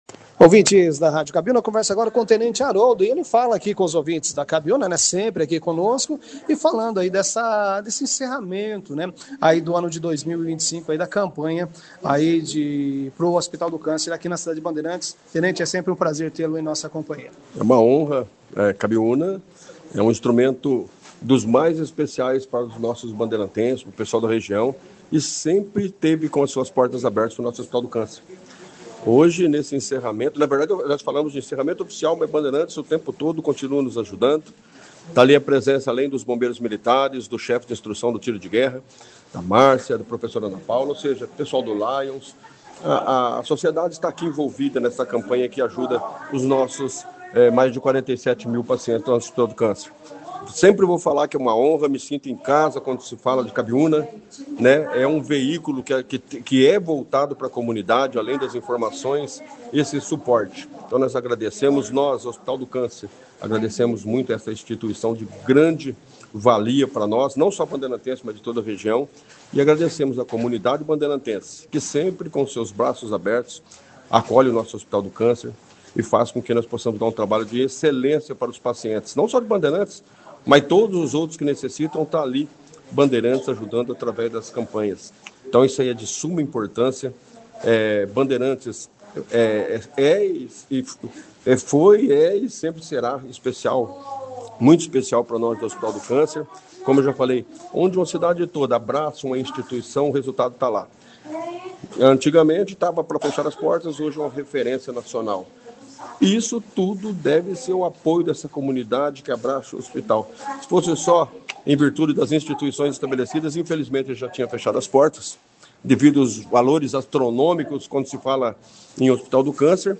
O resultado do esforço conjunto foi impressionante: com mais de 3 toneladas arrecadas A entrega das doações foi destaque na 2ª edição do Jornal Operação Cidade, nesta terça-feira, 16de dezembro.